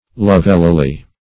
Search Result for " lovelily" : The Collaborative International Dictionary of English v.0.48: Lovelily \Love"li*ly\, adv.